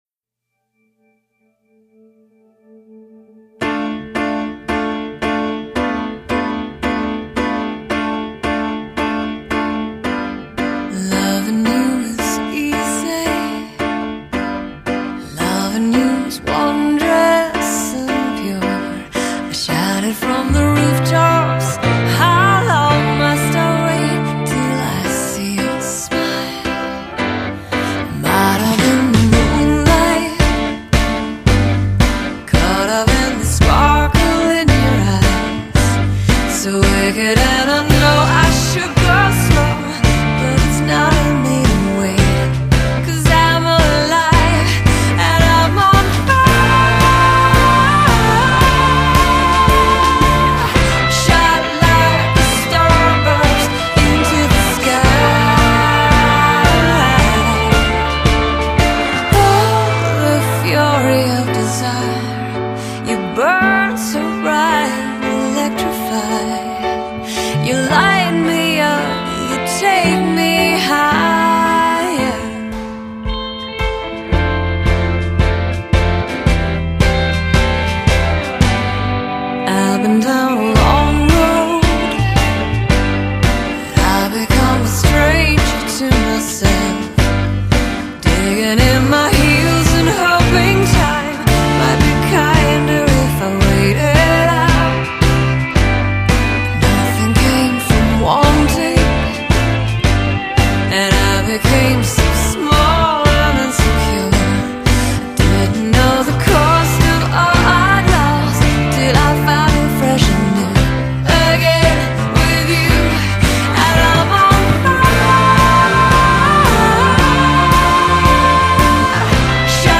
音乐风格: 流行
散发清新脱俗的优雅气质，倚著独具魅力的空灵式唱腔，
以飞扬的跳跃琴韵当根基
随后层次铺入不过分的电吉他和弦乐辅佐